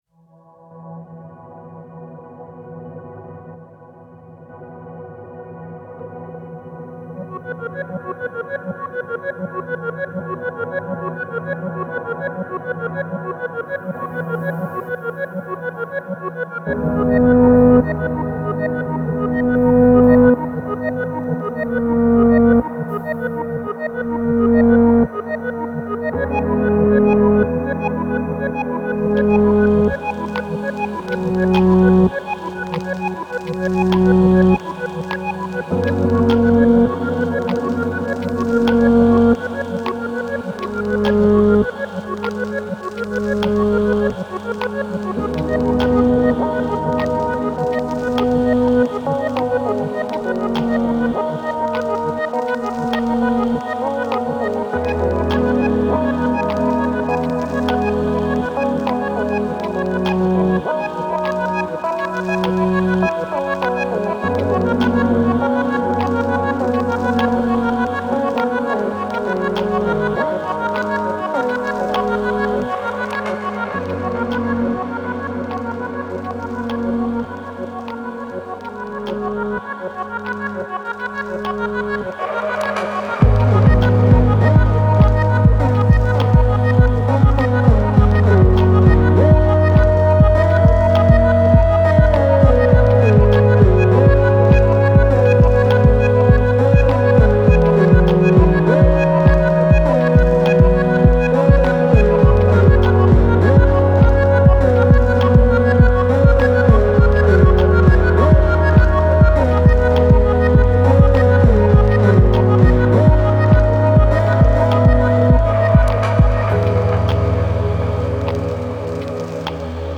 Granular synths, textural crackles and glitchy pulses.